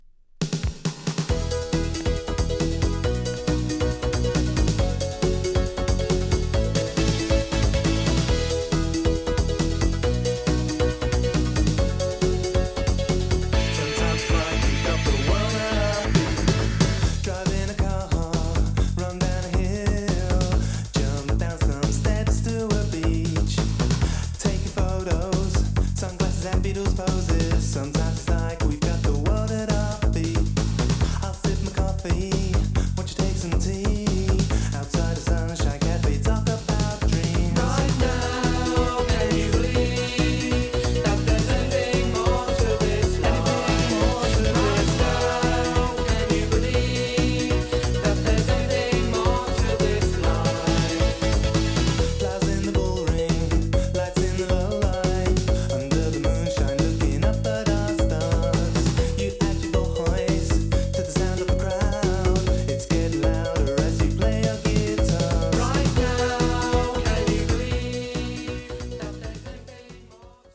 刹那気エレボッサ
ドリーミーな